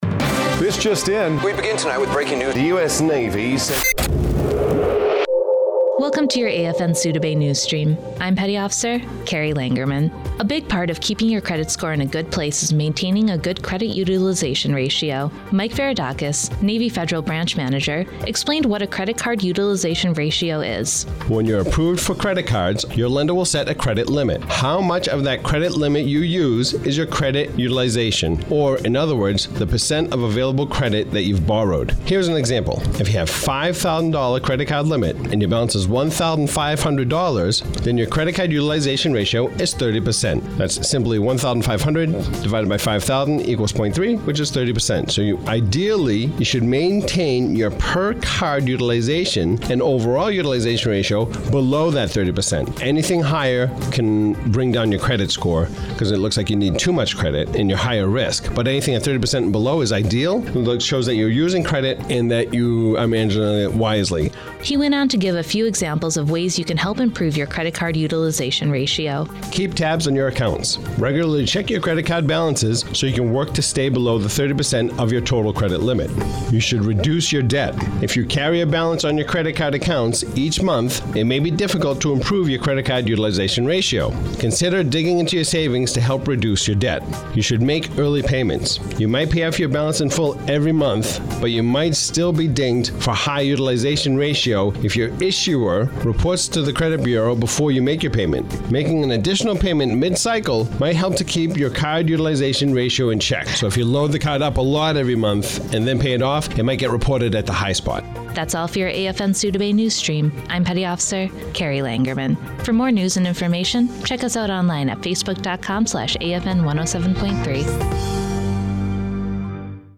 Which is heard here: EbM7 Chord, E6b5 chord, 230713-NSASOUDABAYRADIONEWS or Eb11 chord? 230713-NSASOUDABAYRADIONEWS